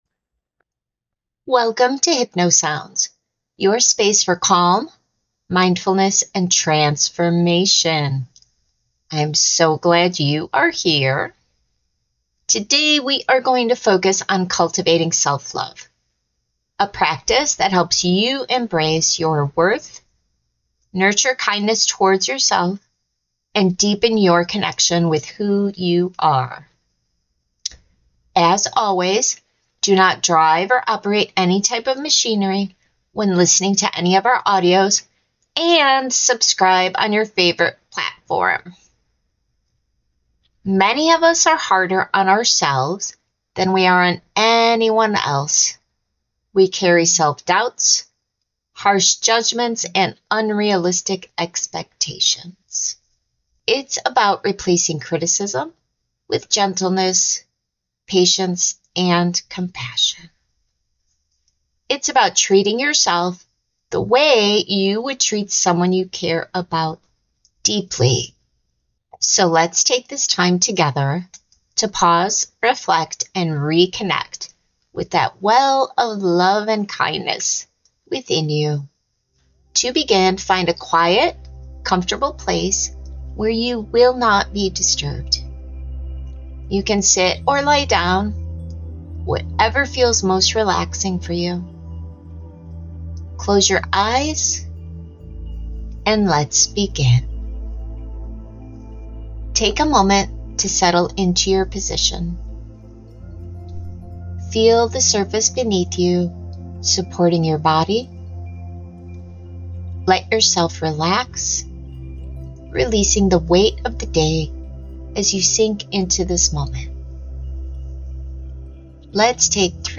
Latest was 😴🌊 Listen to a STREAM flowing gently for deep sleep.